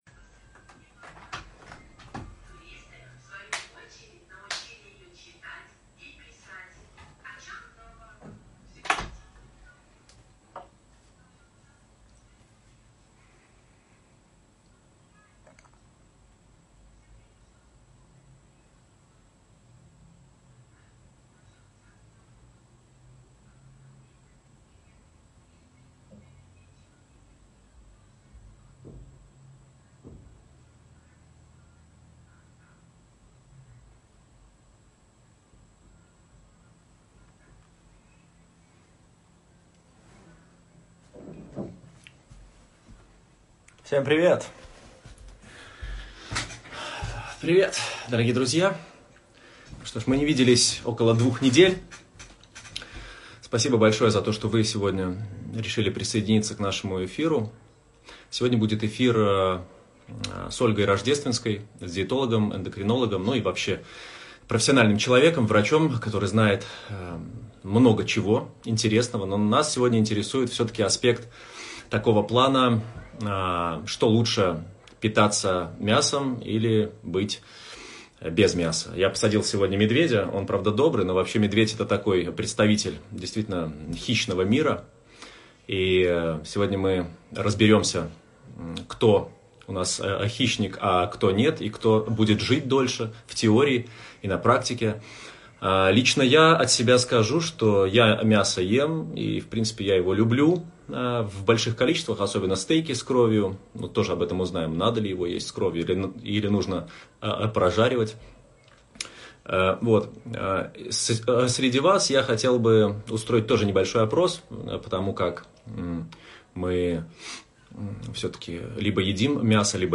Источник: Персональное интервью